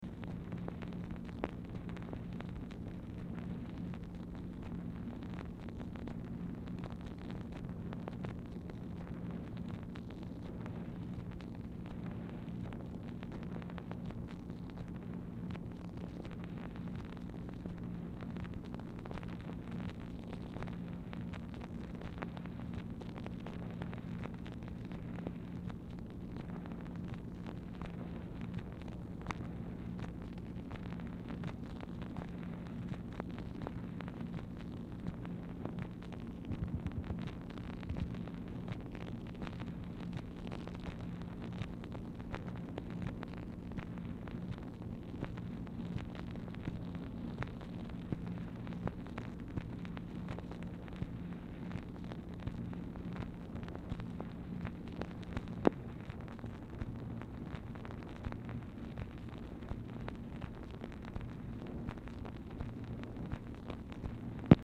Telephone conversation # 2920, sound recording, MACHINE NOISE, 4/8/1964, time unknown | Discover LBJ
Telephone conversation
Format Dictation belt